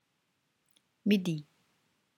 The listening will help you with the pronunciations.